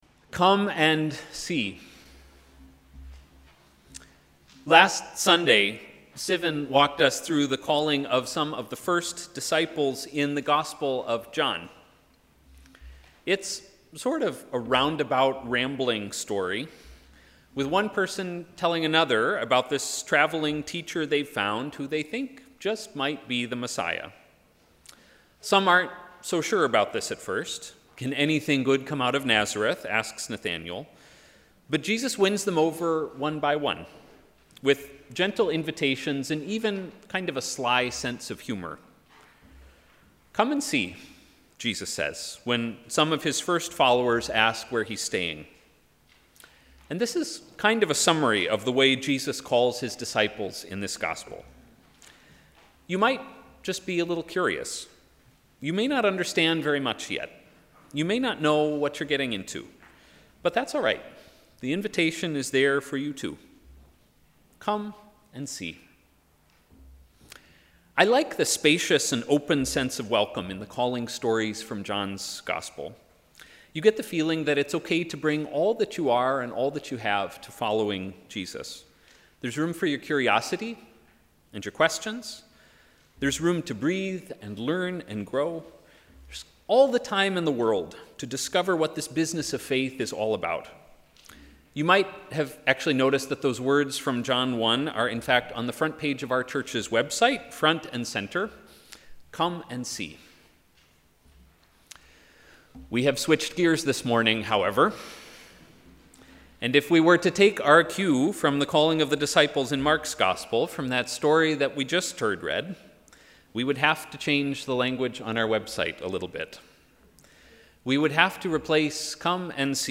Sermon: ‘Immediately’
Sermon on the 3rd Sunday after Epiphany